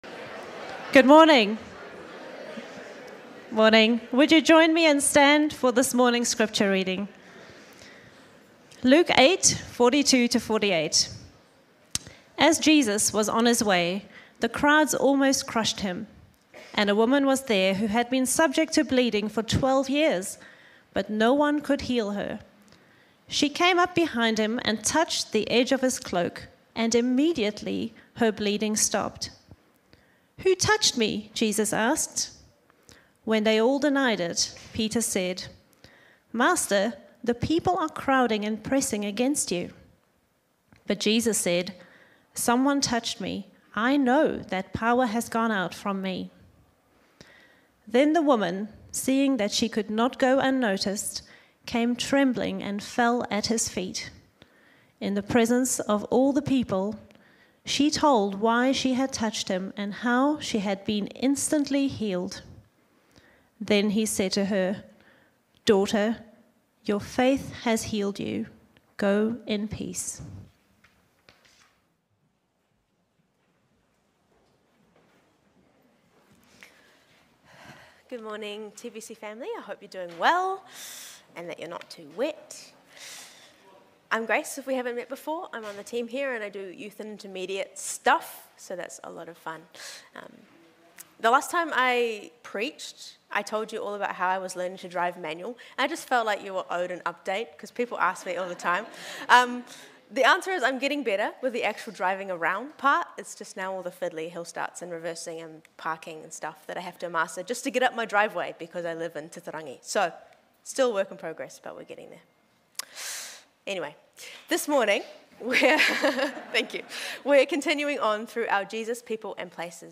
Sermons | Titirangi Baptist Church
As we bring these stories to life through the synergy of preaching and dramatic performance, we pray that it stirs a desire in all of us to extend the life-changing gospel of Jesus to those around us, fostering a community rooted in compassion and action. Today we are looking at Luke 8:43-48 where the beauty of the Gospel is revealed by the courageous faith of the unclean.